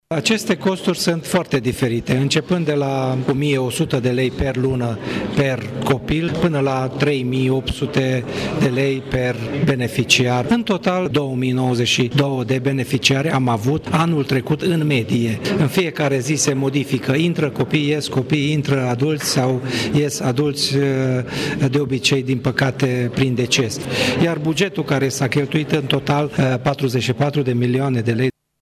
Directorul Direcției Generale de Asistență Socială și Protecția Copilului Mureș, Schmidt Lorand, a precizat că instituția deservește, în județ o medie de peste 2.000 de beneficiari, copii și adulți: